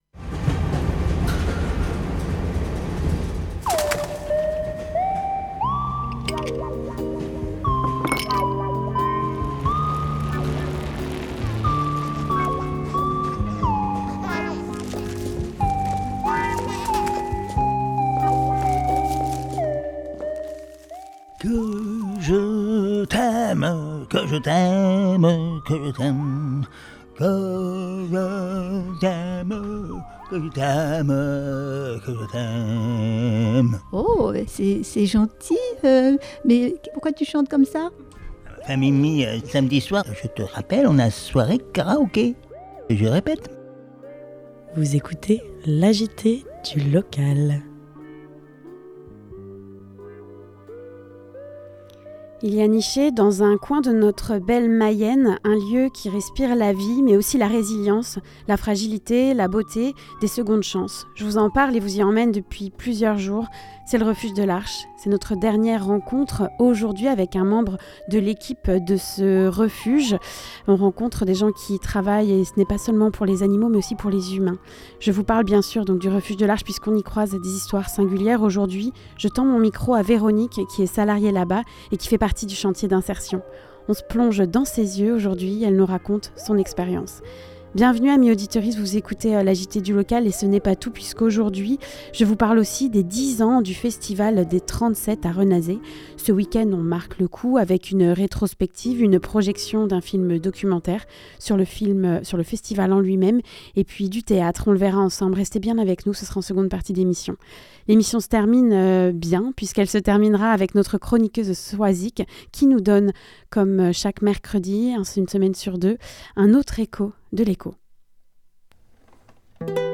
La petite annonce au Bout Du Fil Le reportage aujourd'hui nous emmène à L'avant première du film, Un tapis rouge dans le pré , réalisé par Jean François Castell , cette projection a eu lieu lors du Festival Premier Plan à Angers et nous y étions.